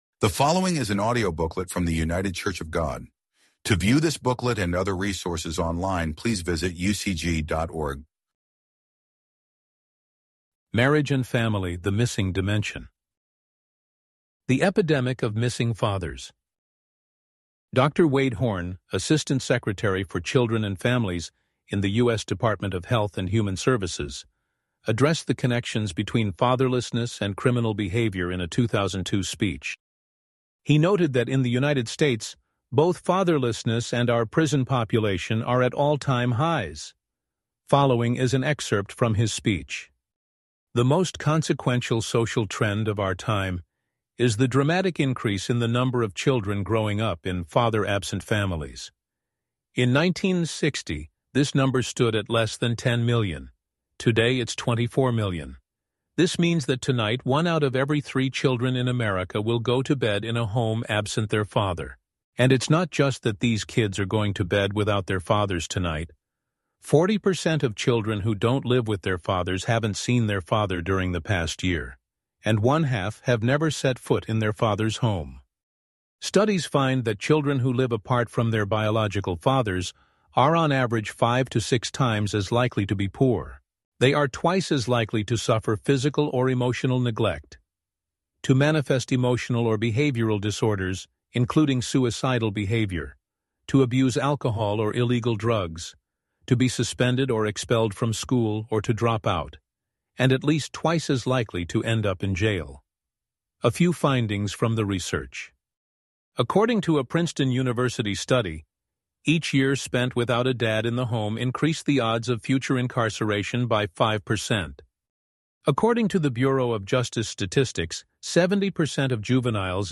A seguir é um trecho do seu discurso: